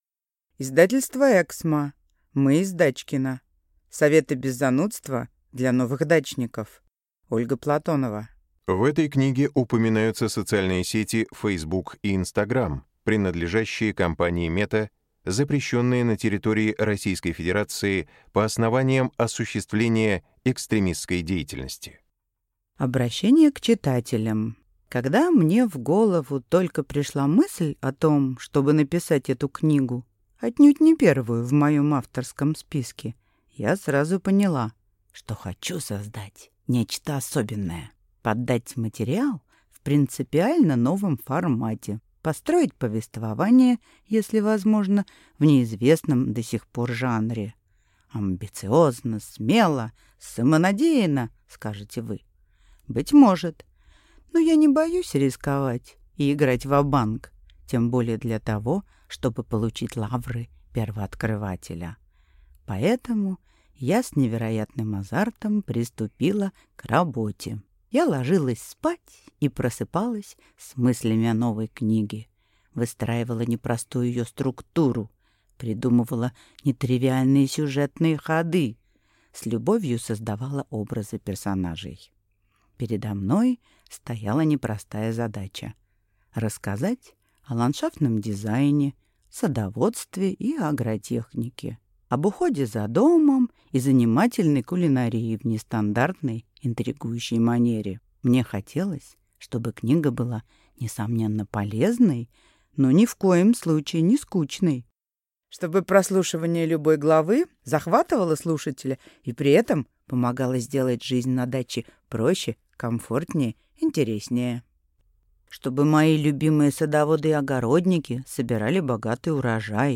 Аудиокнига Мы из Дачкино. Советы без занудства для новых дачников | Библиотека аудиокниг